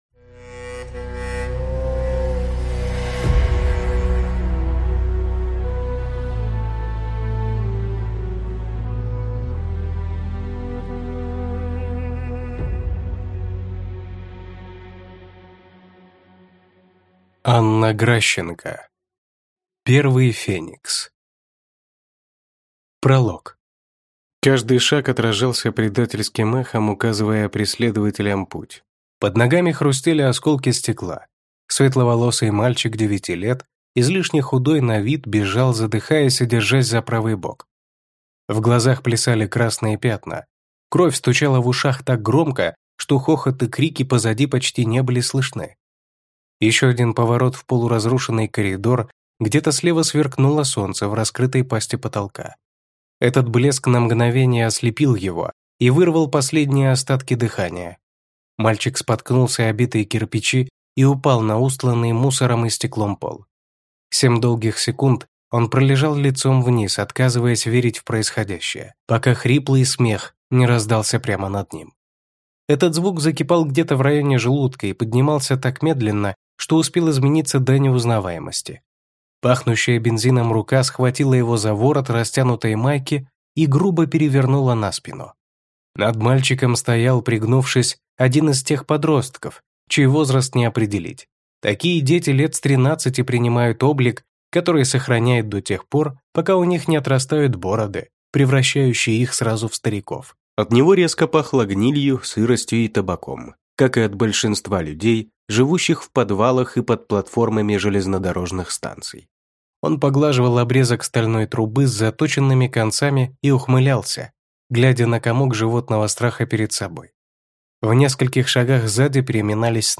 Аудиокнига Первый Феникс | Библиотека аудиокниг